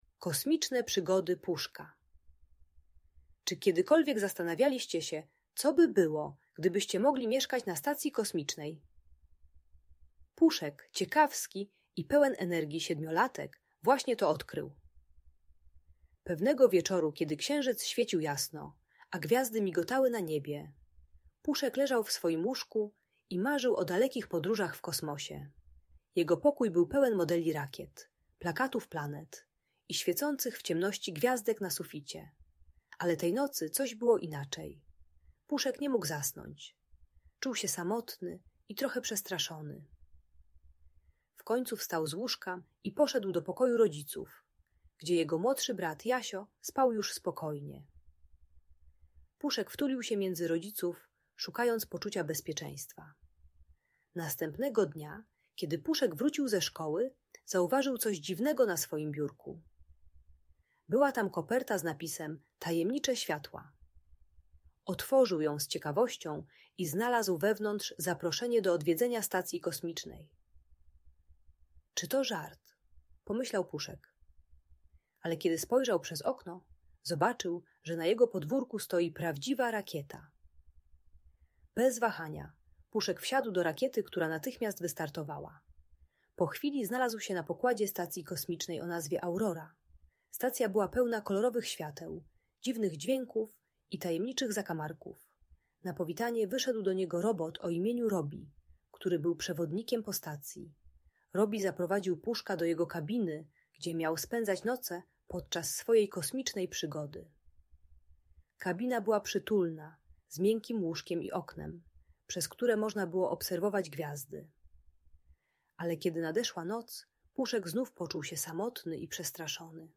Kosmiczne Przygody Puszka - Audiobajka